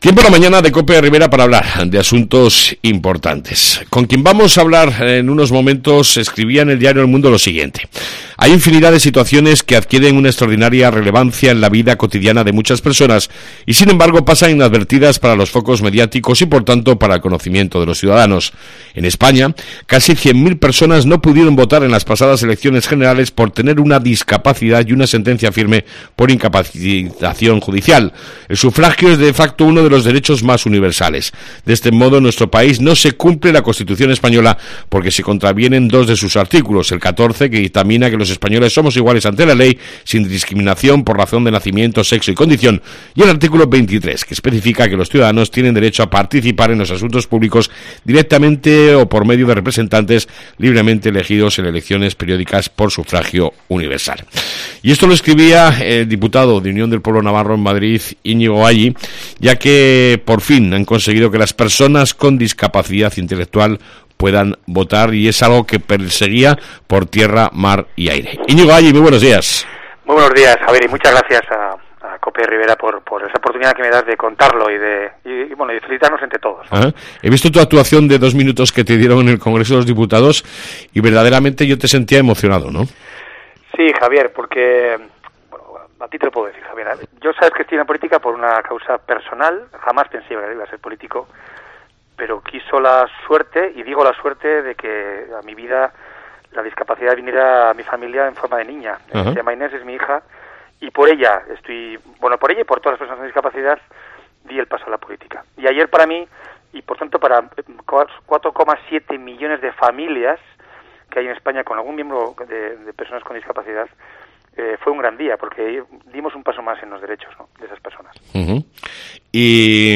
Entrevista con el Diputado de UPN Iñigo Alli